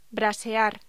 Locución: Brasear